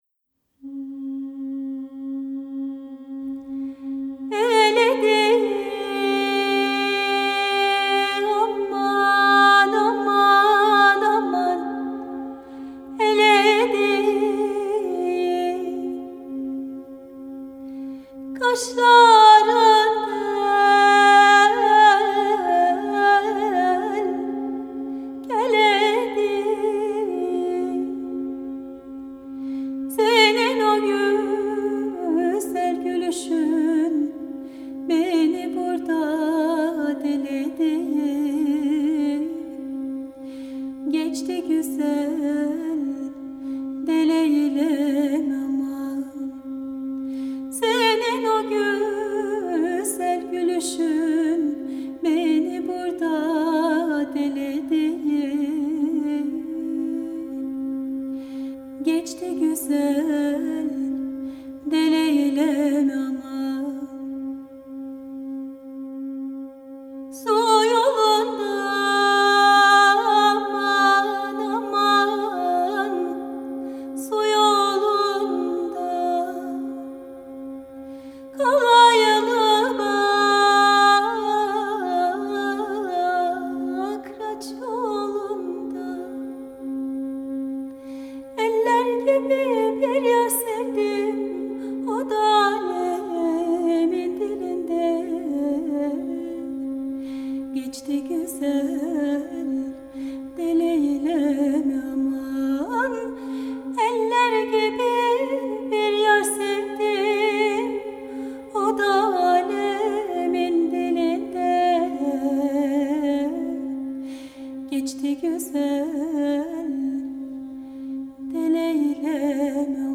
Genre: World, Balkan Music